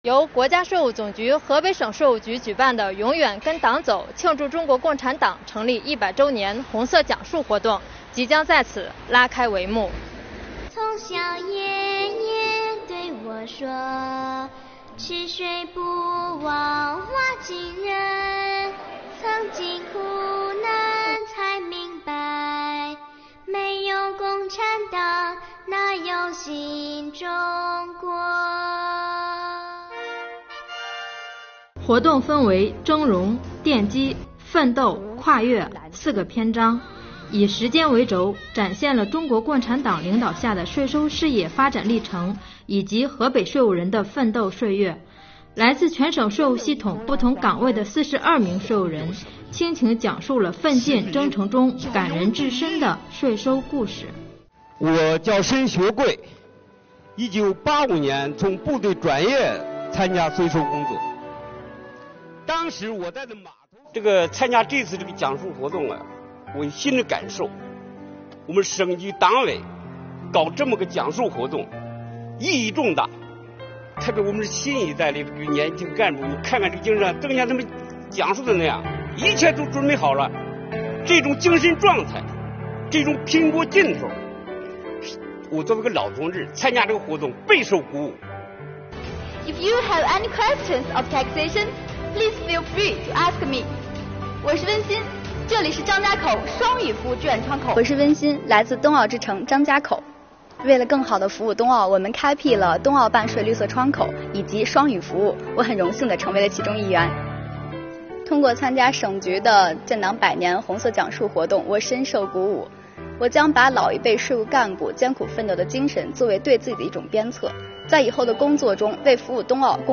为纪念中国共产党成立100周年，推动党史学习教育深入开展，日前，国家税务总局河北省税务局在石家庄大剧院举办“永远跟党走”大型红色讲述活动。
“前线需要啥，俺们就捐啥。”“解放区的乡亲们踊跃缴税纳粮，宁可自己吃糠面饼子，也要把家里粮食捐出来；宁可自己冻着脚，也要把新棉鞋送给前线战士”，讲述以“峥嵘”主题开篇，随着税务干部的深情讲述，大家被战争年代军民一心、共抗顽敌的精神所感染，不时爆发出热烈的掌声。